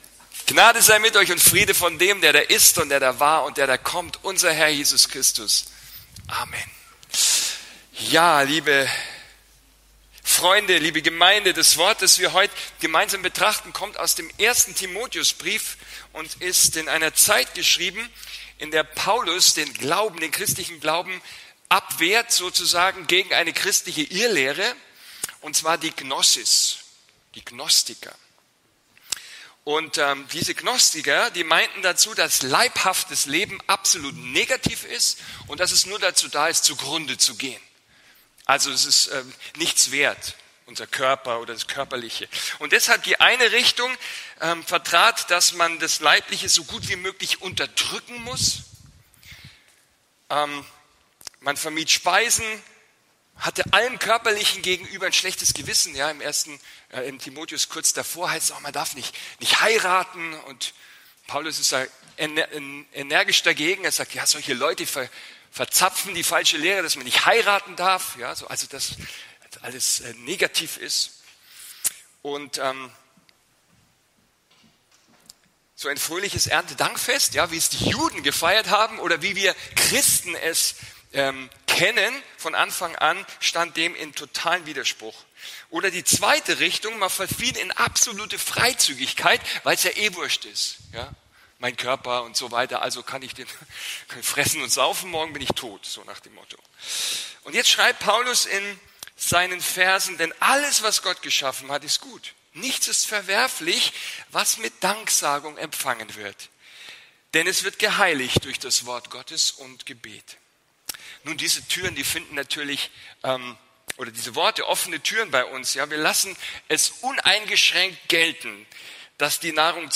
Ein Studienblatt zur Predigt ist im Ordner “Notizen” (Dateien zum Herunterladen) verfügbar